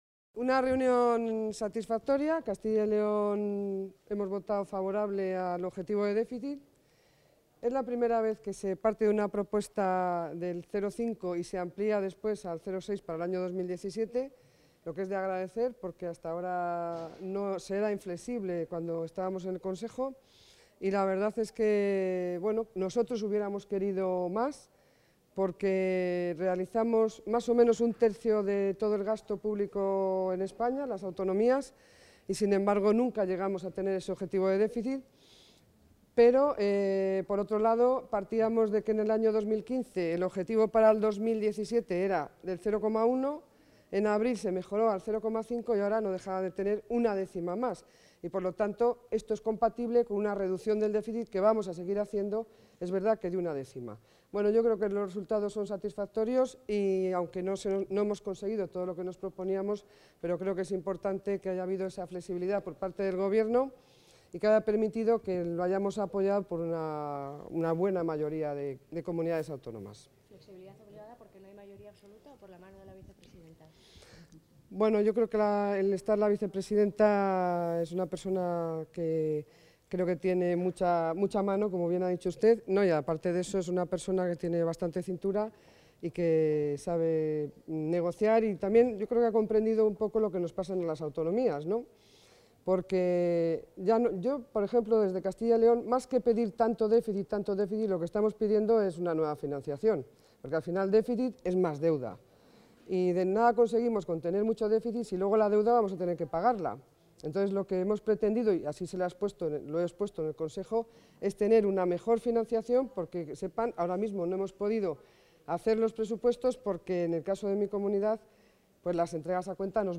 Declaraciones de la consejera de Economía y Hacienda después del Consejo de Política Fiscal y Financiera
Declaraciones de la consejera de Economía y Hacienda después del Consejo de Política Fiscal y Financiera Contactar Escuchar 1 de diciembre de 2016 Castilla y León | Consejería de Economía y Hacienda Se adjunta audio con las declaraciones de la consejera de Hacienda, Pilar del Olmo, después de asistir a la reunión del Consejo de Política Fiscal y Financiera.